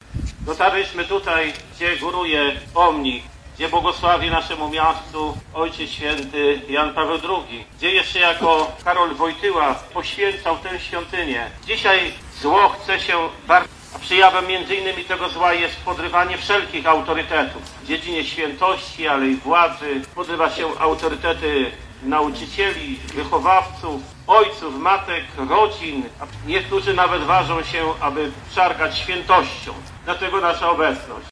Pod pomnikiem Papieża Polaka odbyła się wspólna modlitwa